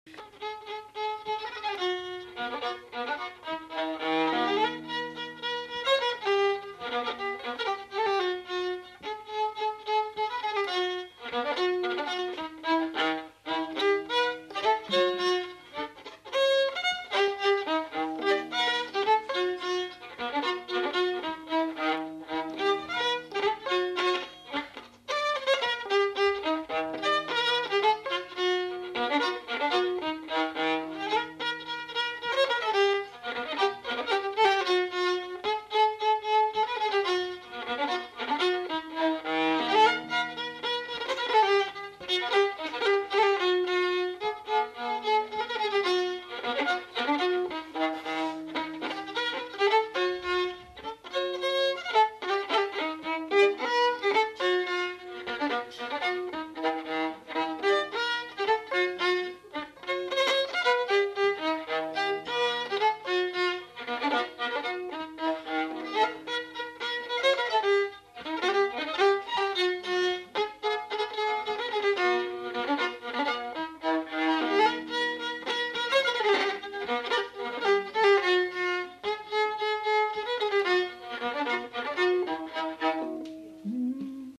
Lieu : Saint-Michel-de-Castelnau
Genre : morceau instrumental
Instrument de musique : violon
Danse : polka